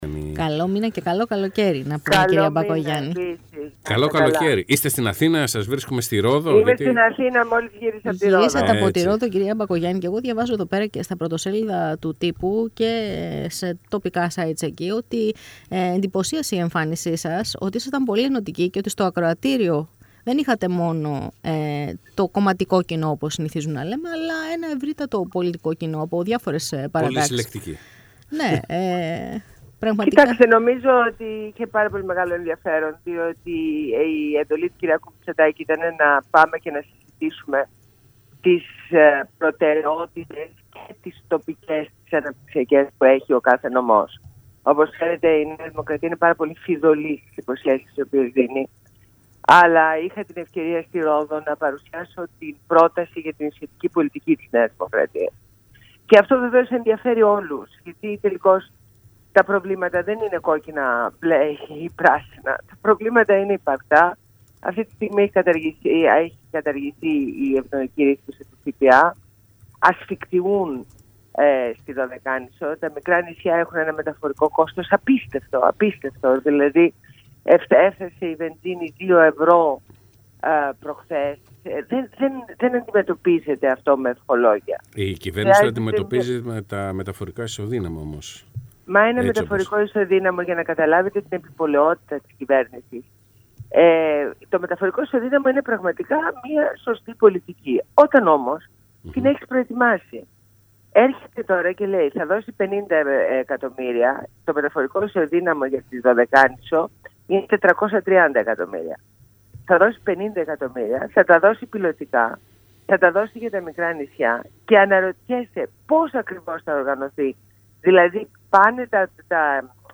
Συνέντευξη στο ραδιόφωνο Πρακτορείο 104,9 (Θεσσαλονίκη)